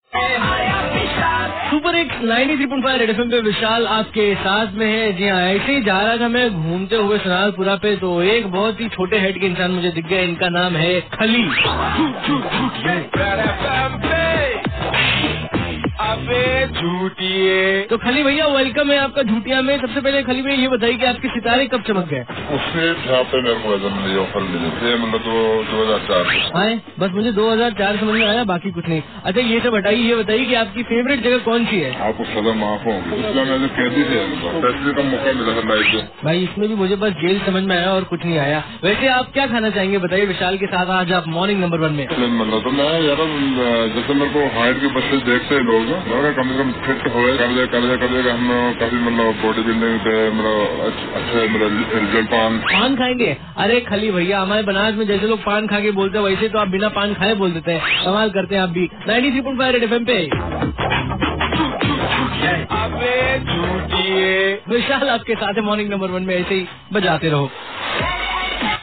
interviewed The Great Khali